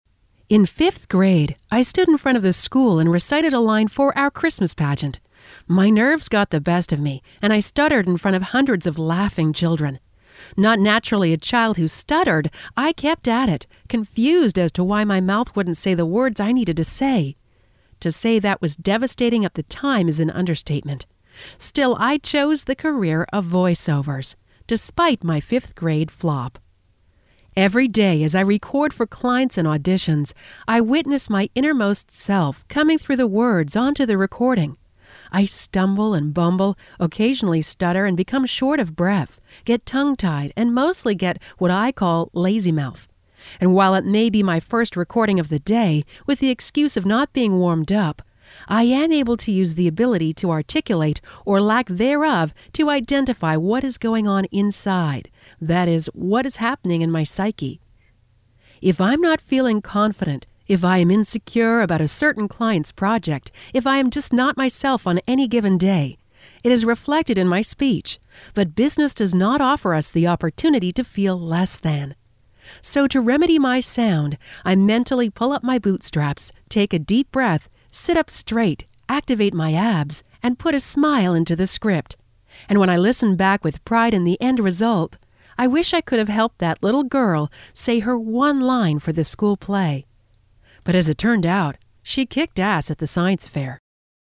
I Have Confidence!* (audio version of blog below) In 5th grade, I stood in front of the school and recited a line for our Christmas pageant.